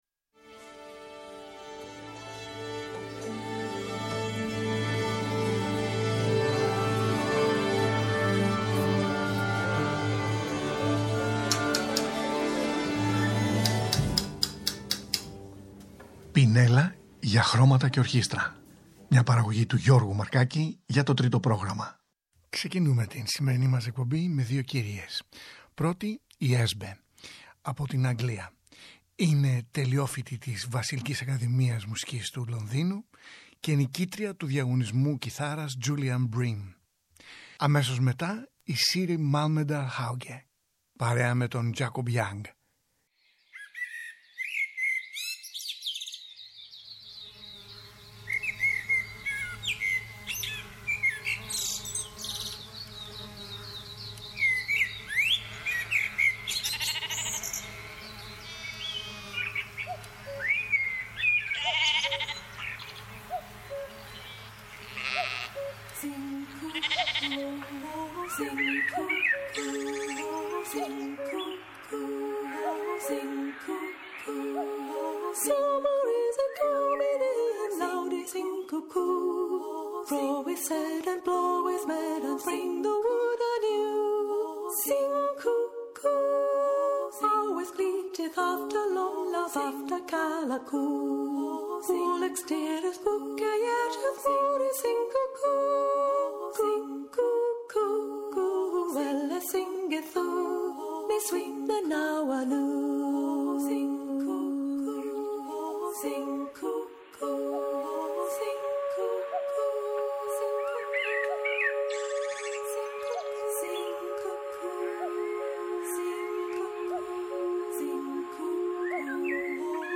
Folk , παραδοσιακής μουσικής